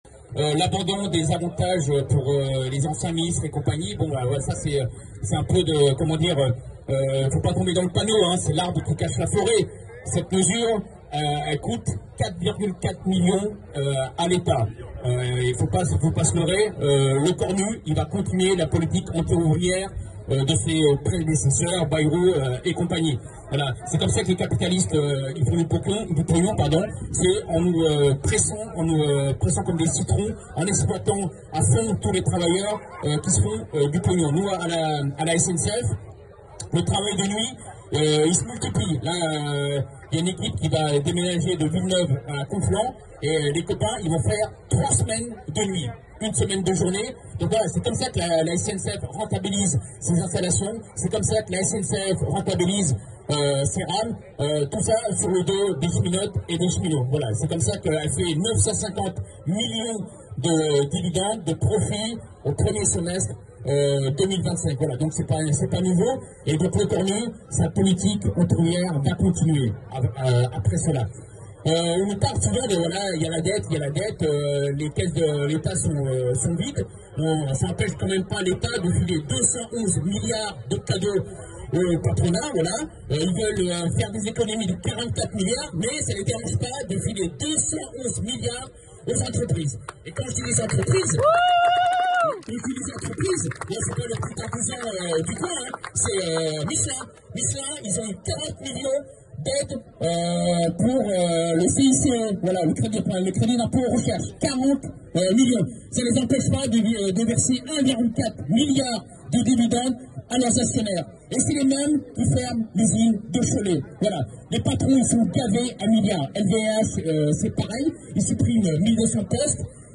Intervention